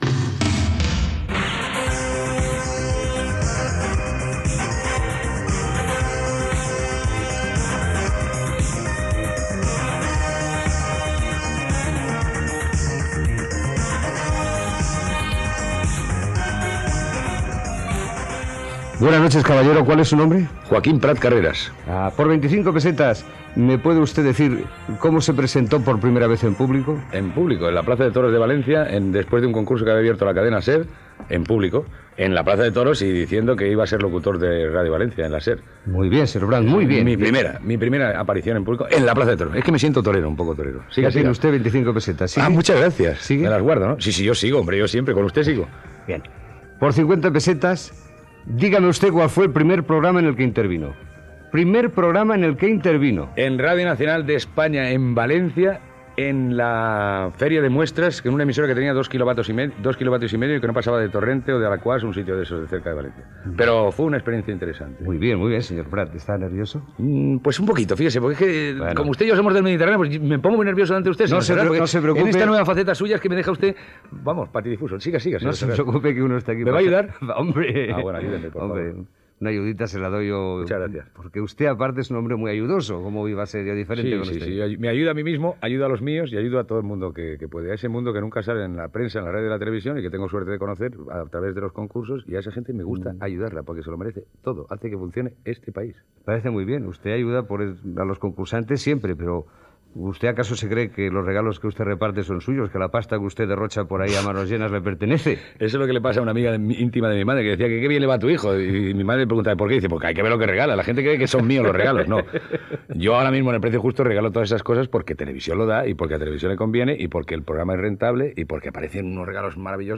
Entrevista a Joaquín Prat, en aquell moment presentador de "El precio justo" a TVE, sobre la seva carrera radiofònica